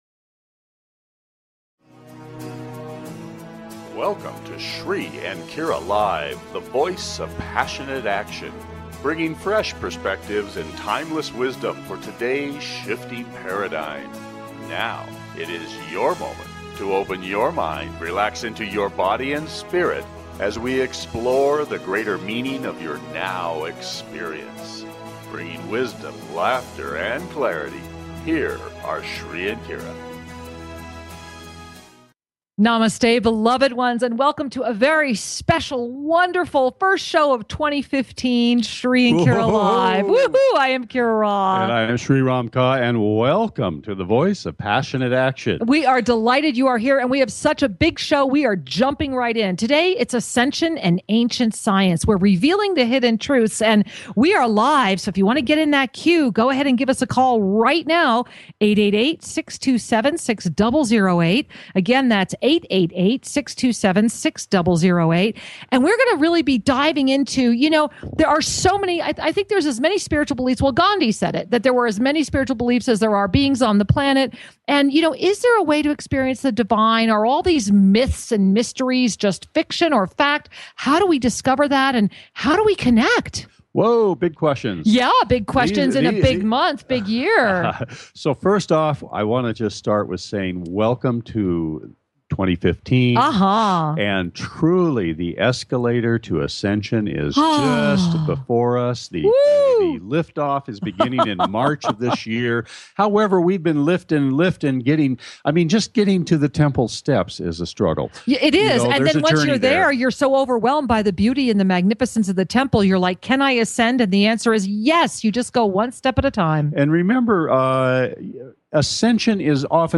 This is a fascinating show with open phone lines!